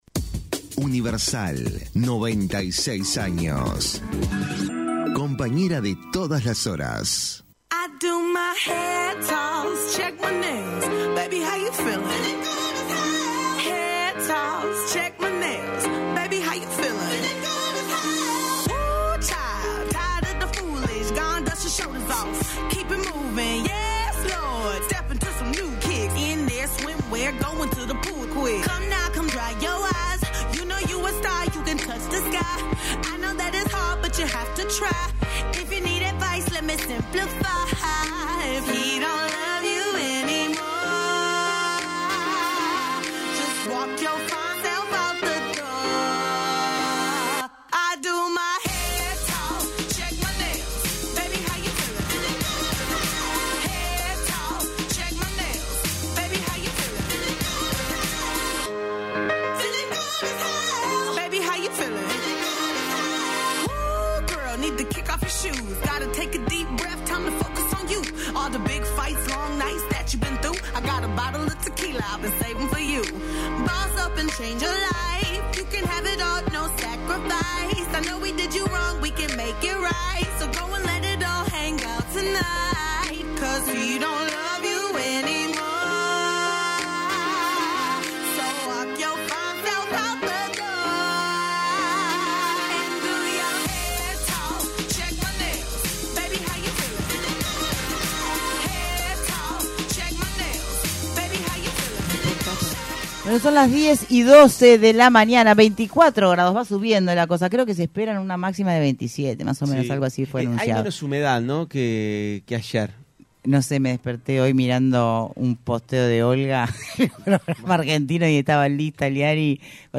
¿Por qué se compran obras de arte? Entrevista